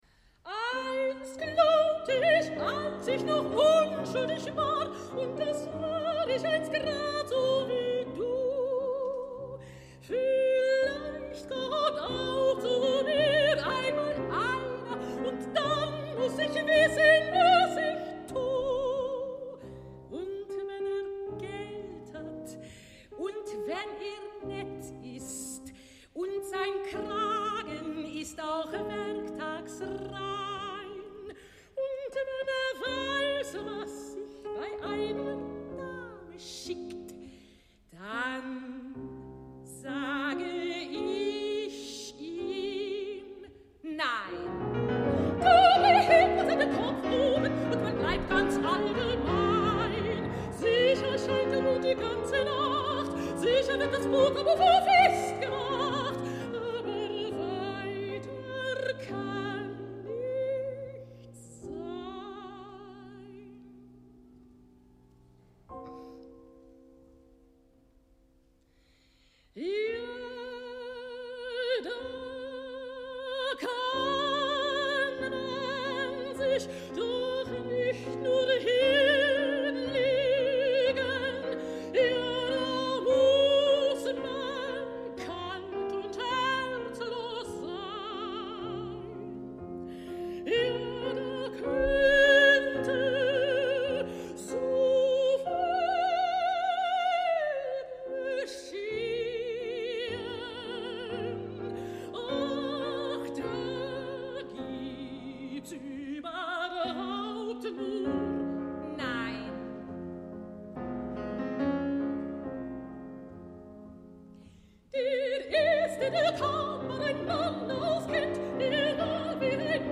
Großer Saal, Wiener Konzerthauses